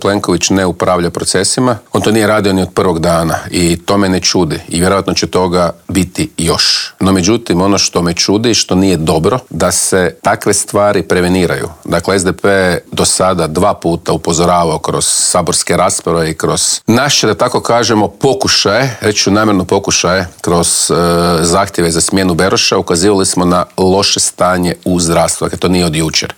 "Na idućim parlamentarim izborima SDP će biti prvi, kandidirao sam se za predsjednika stranke da budem premijer", otkrio nam je u Intervjuu tjedna Media servisa predsjednik najjače oporbene stranke Siniša Hajdaš Dončić.